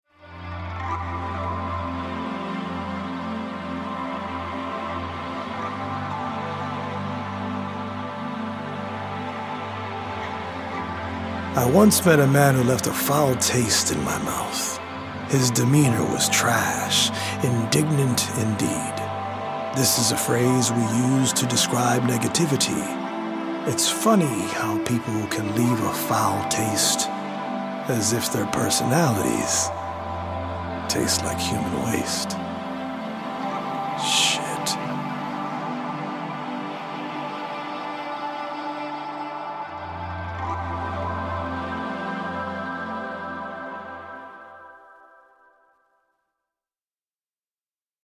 original poems written/performed
healing Solfeggio frequency music
EDM